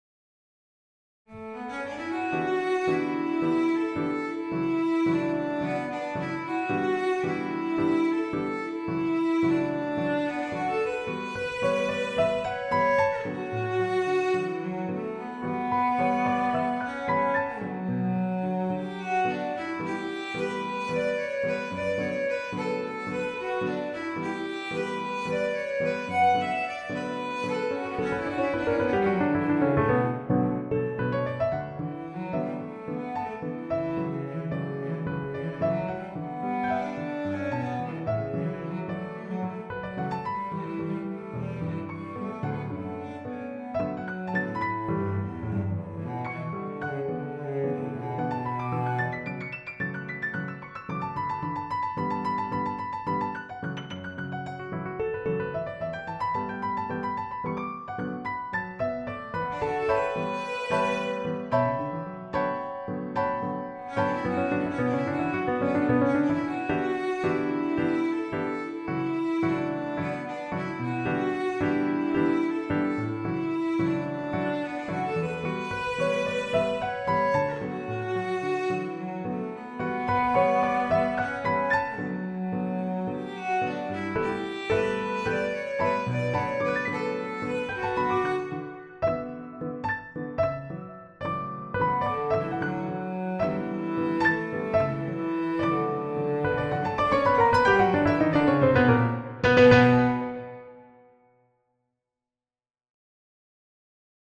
for cello and piano
This piece is exactly like that: a couple of minutes of uncomplicated life.
Short, sweet and not obvious.
Enchanting, delicate, and full of life.